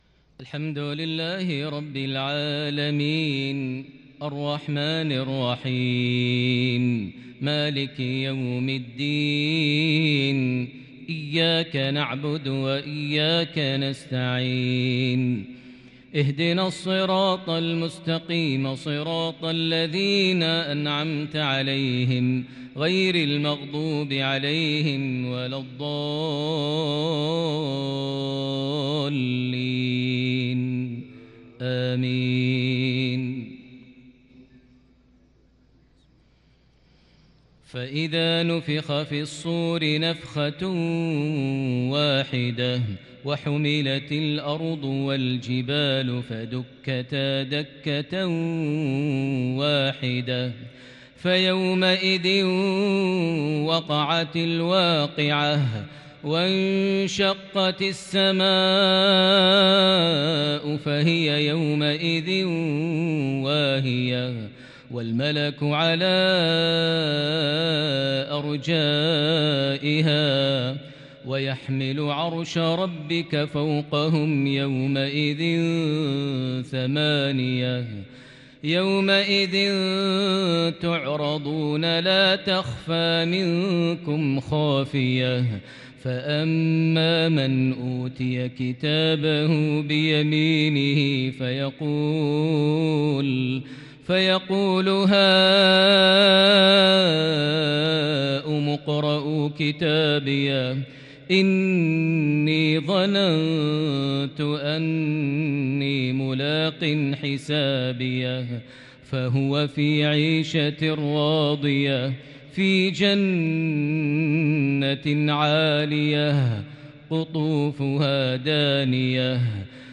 مغربية شجية من سورة الحاقة (13-43) | الخميس 9 جمادى الأول 1442هـ > 1442 هـ > الفروض - تلاوات ماهر المعيقلي